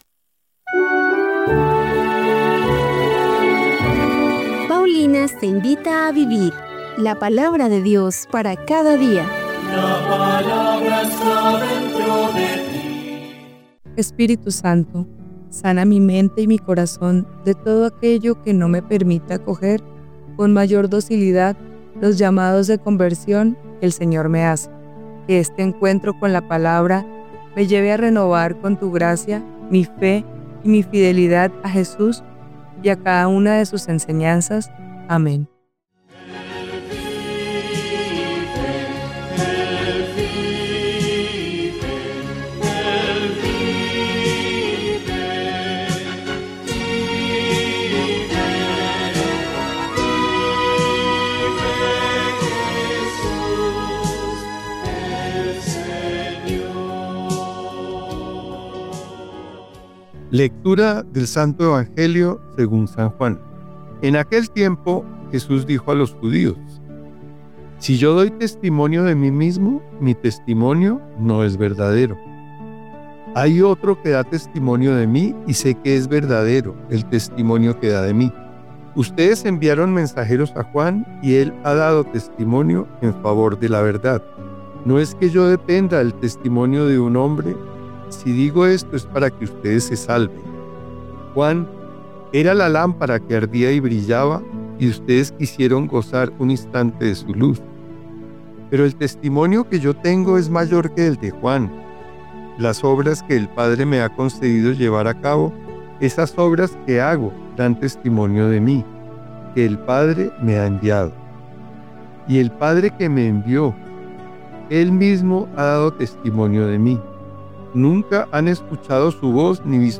Lectura de los Hechos de los Apóstoles 3, 1-10